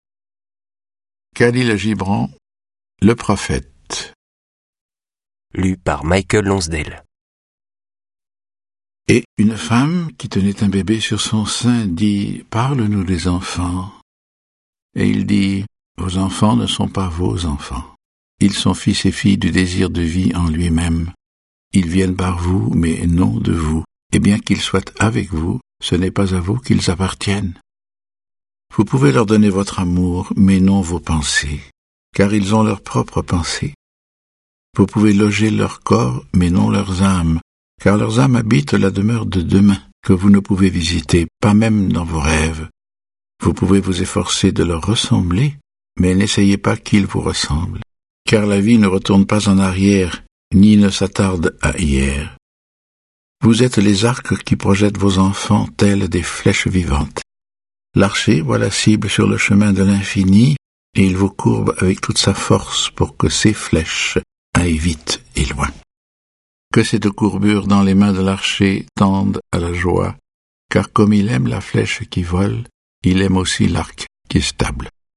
Diffusion distribution ebook et livre audio - Catalogue livres numériques
Sa voix alliée à un superbe texte donne au Prophète une résonnance inoubliable.